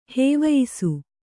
♪ hēvayisu